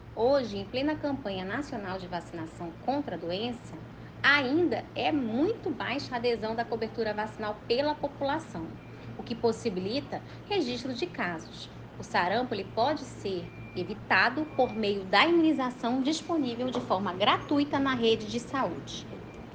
SONORA: Tatyana Amorim, diretora-presidente da FVS-RCP